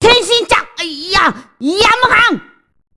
dbz2_goku_yamhan.mp3